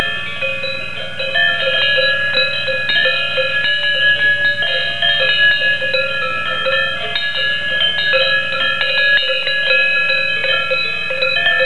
concert de cloches (.mp3) [3] lupins lupins